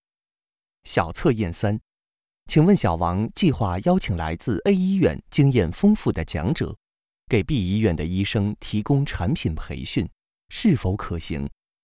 Chinese_Male_005VoiceArtist_20Hours_High_Quality_Voice_Dataset